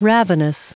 Prononciation du mot ravenous en anglais (fichier audio)
Prononciation du mot : ravenous